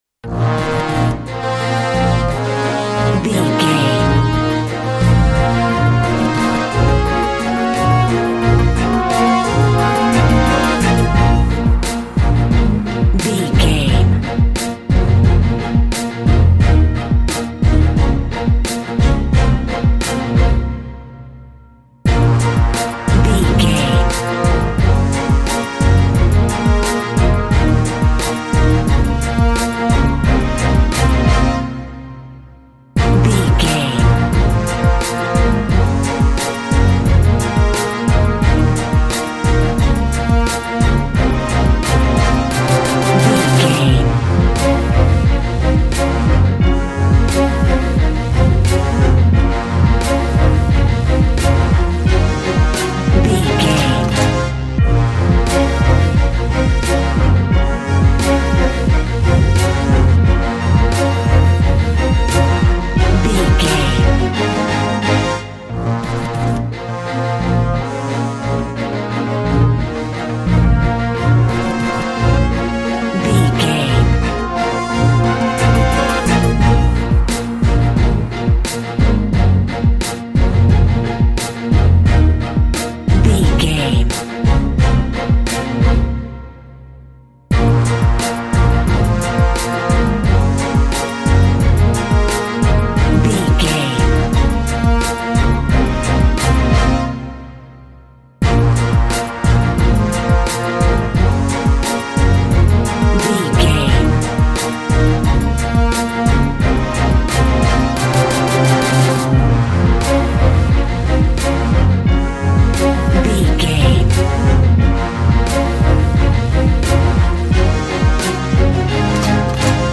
Fast paced
Aeolian/Minor
B♭
strings
drums
horns
hip hop
soul
Funk
acid jazz
energetic
bouncy
funky
hard hitting